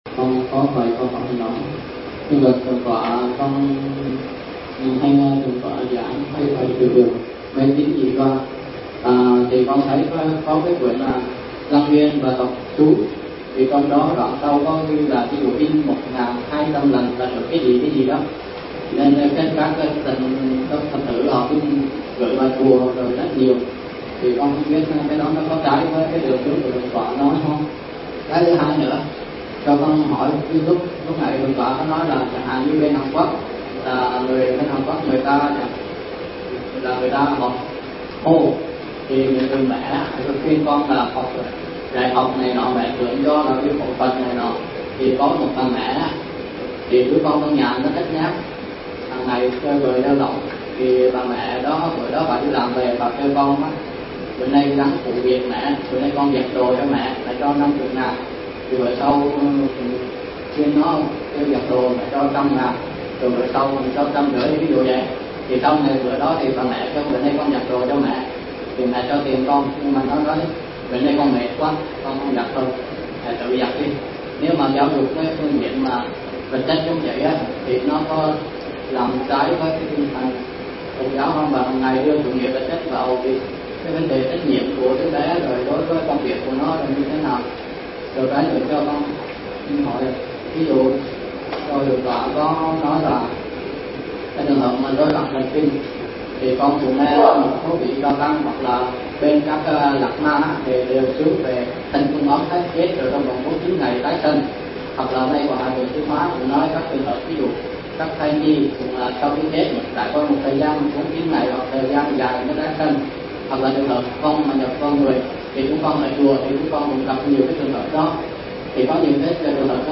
Nghe mp3 Vấn đáp